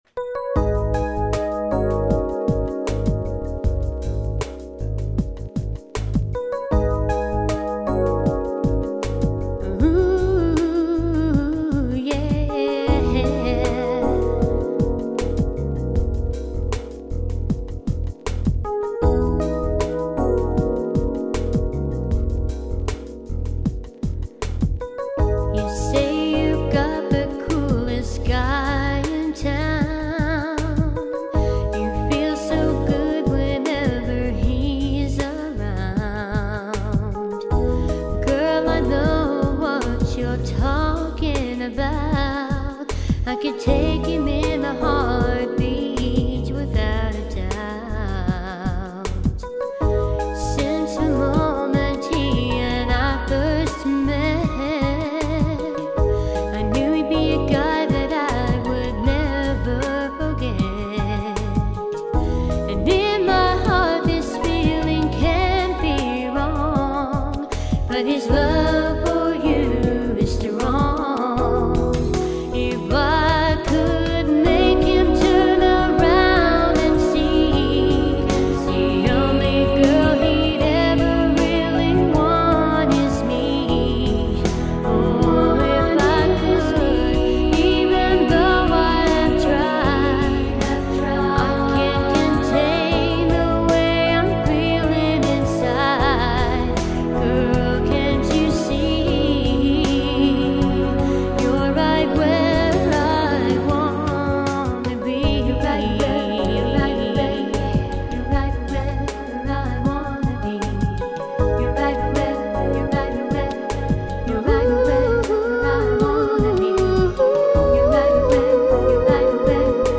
Adult Contemporary Originals